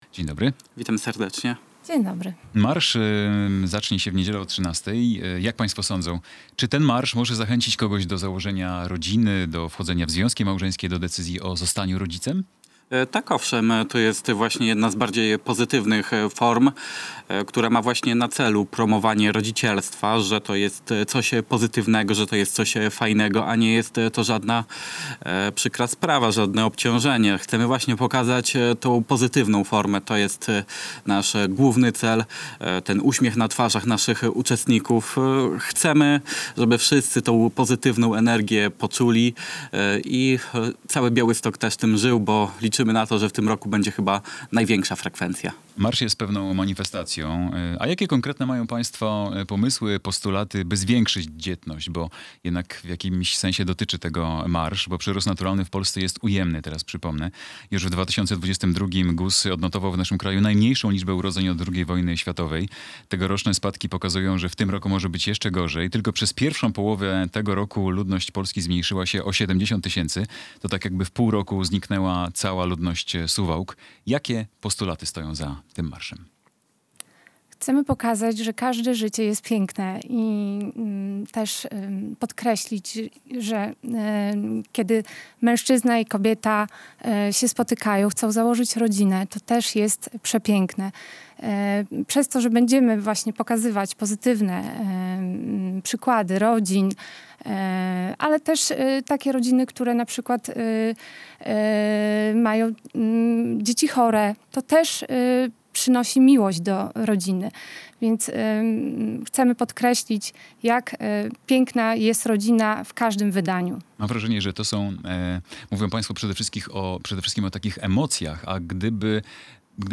Gość